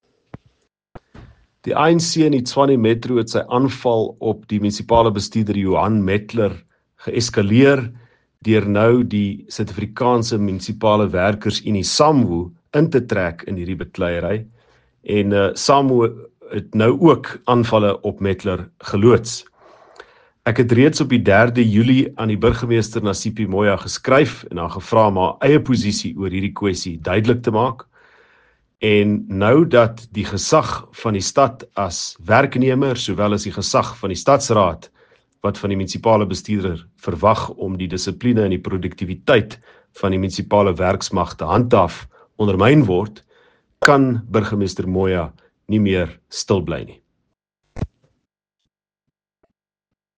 Note to Editors: Please find English and Afrikaans soundbites by Ald Cilliers Brink